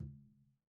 Tumba-HitN_v1_rr2_Sum.wav